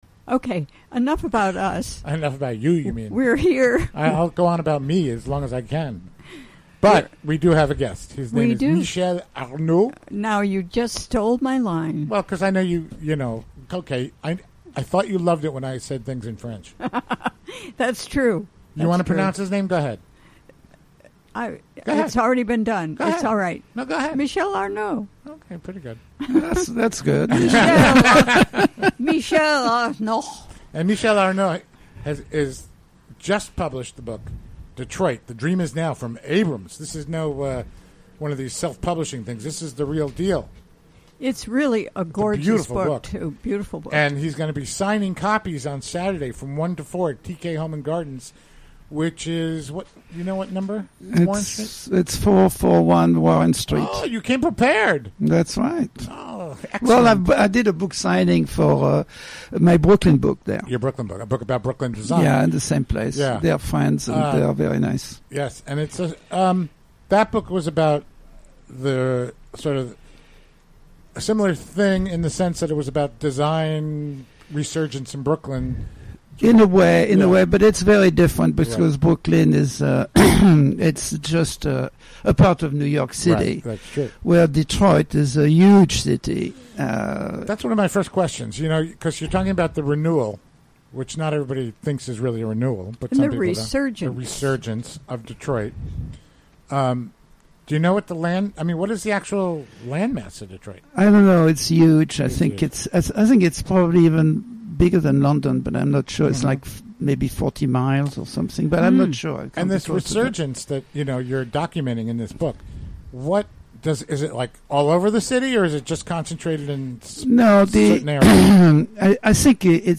Recorded during the WGXC Afternoon Show Thursday, July 6, 2017.